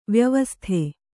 ♪ vyavasthe